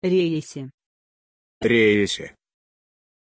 Aussprache von Reese
pronunciation-reese-ru.mp3